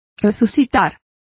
Complete with pronunciation of the translation of waked,woke.